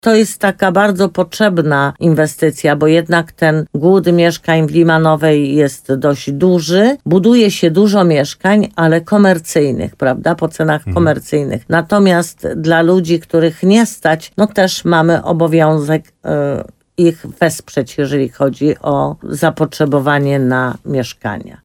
Jak mówi burmistrz Limanowej Jolanta Juszkiewicz, mieszkańcy mocno oczekują na zakończenie budowy 8 bloków.